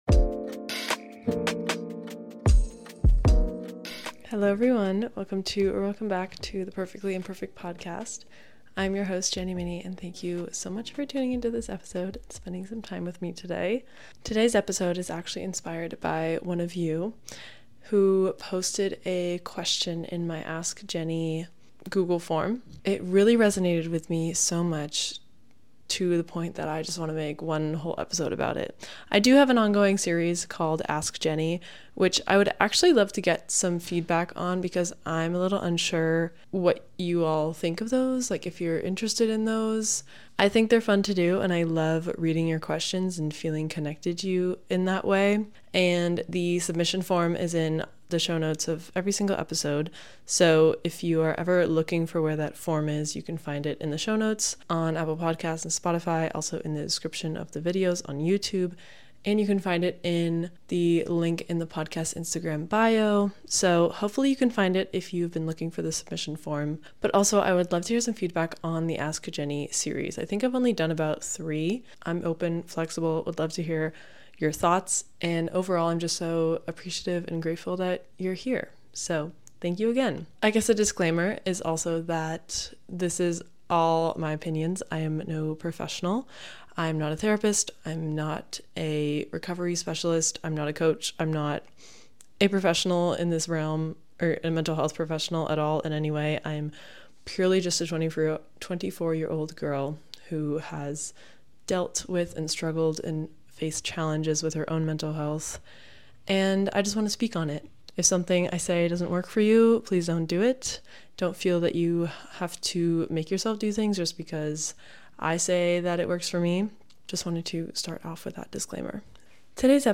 this episode is a bit of a random chatty one, but i wanted to catch you all up on how the last few weeks have been for me.